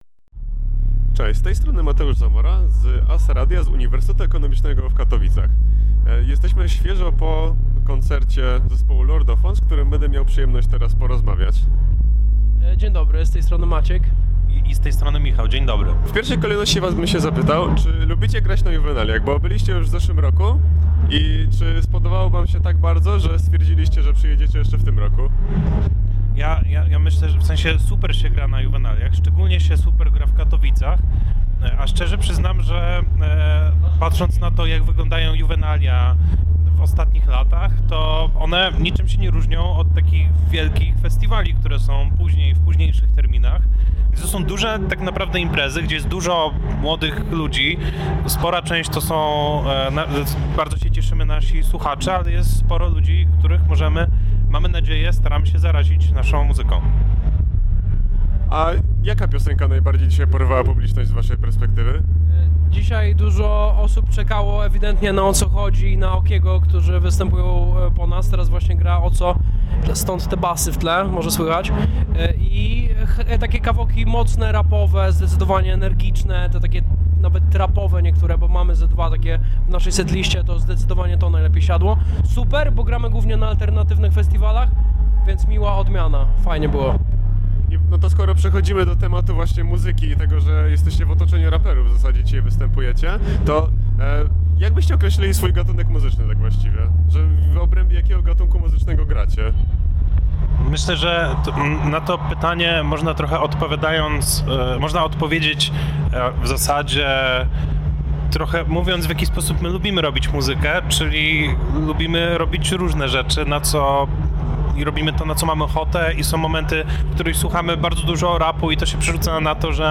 Poznaj Lordofon w zza kulisowym wywiadzie po koncercie na Juwenaliach Śląskich 2025.